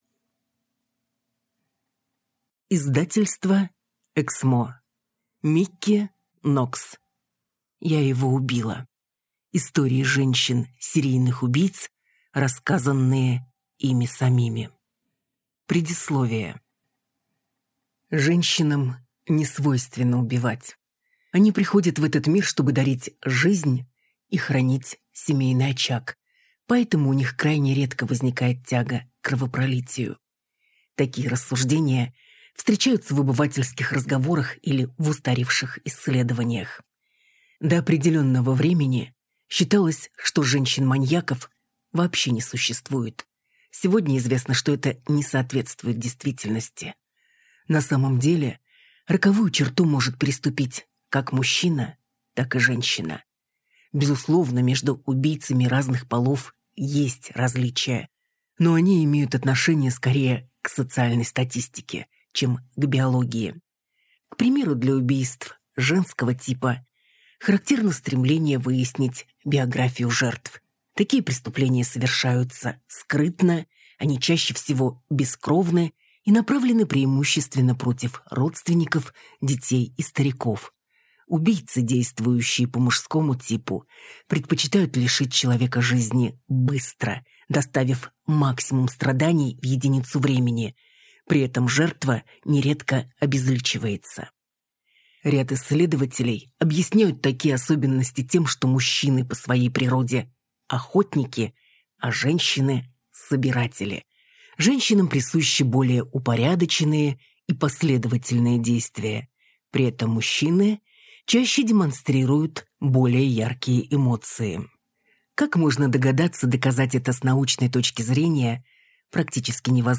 Аудиокнига Я его убила. Истории женщин-серийных убийц, рассказанные ими самими | Библиотека аудиокниг